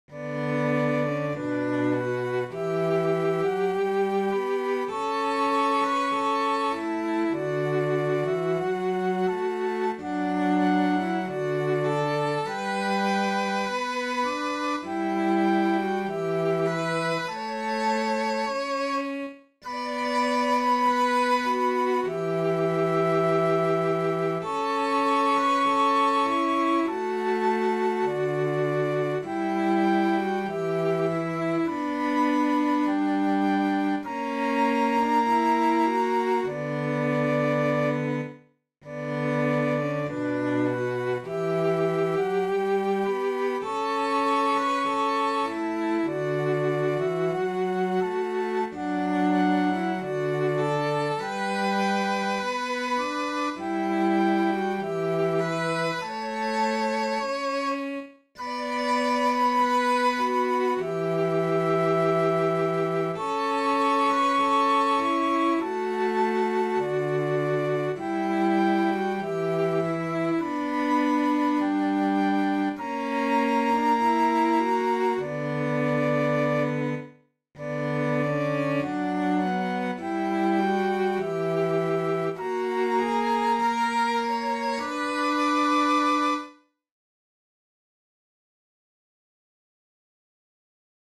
Hiljaista-ja-huomaamatonta-sellot-ja-huilu.mp3